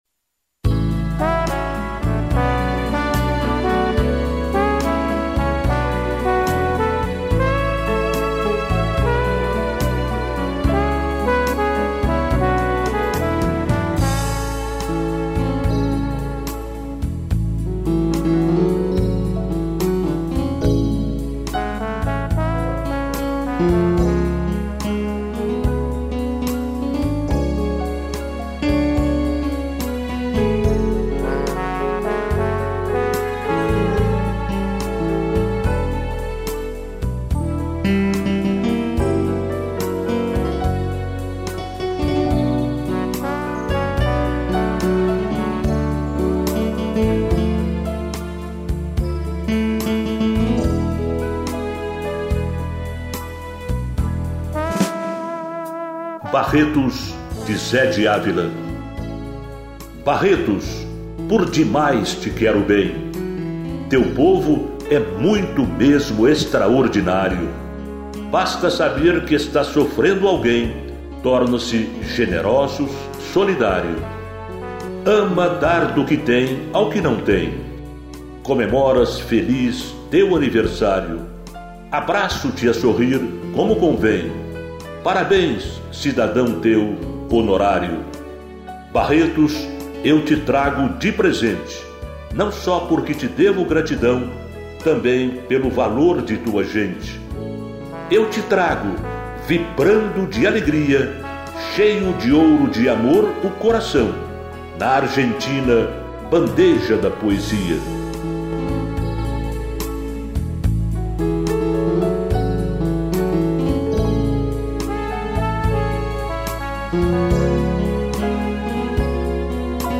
piano, trombone e violino